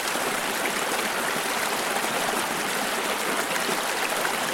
flowing_stream.mp3